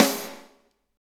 Index of /90_sSampleCDs/Roland L-CD701/SNR_Snares 2/SNR_Sn Modules 2
SNR GRAB 01R.wav